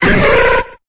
Cri d'Embrylex dans Pokémon Épée et Bouclier.